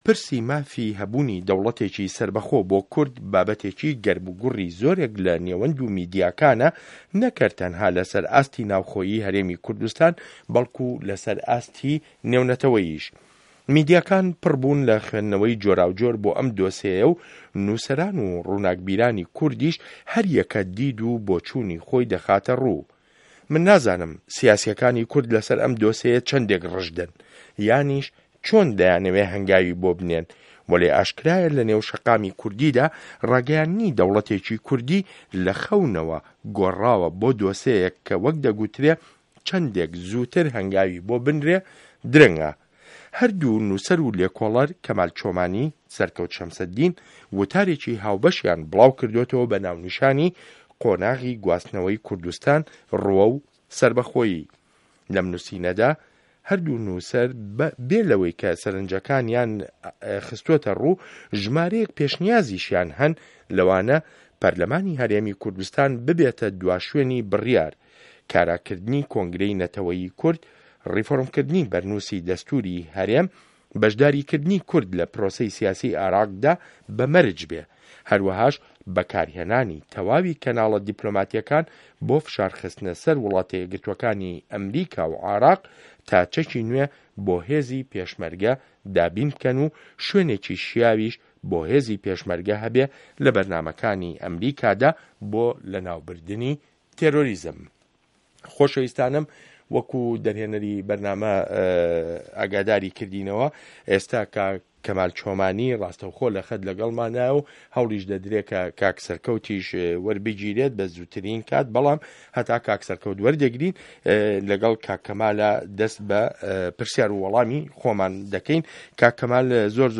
مێزگرد: ده‌وڵه‌تی کوردستان